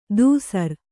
♪ dūsar